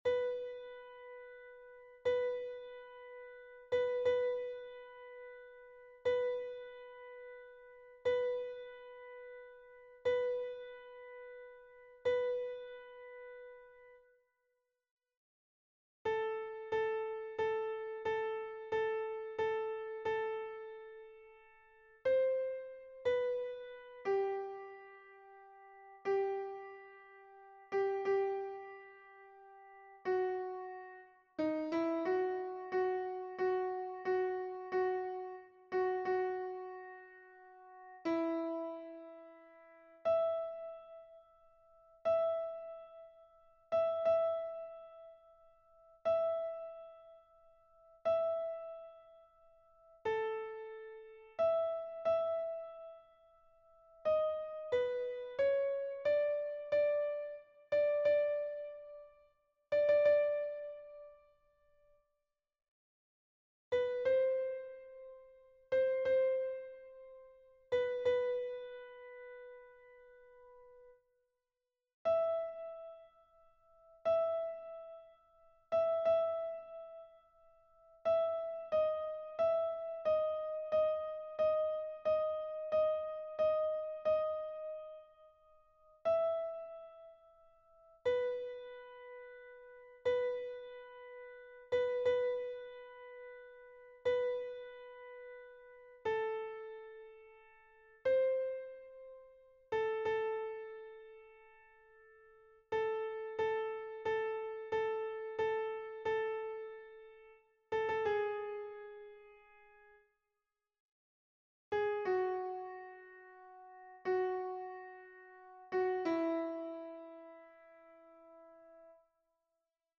Mp3 version piano
MP3 versions rendu voix synth.
Soprano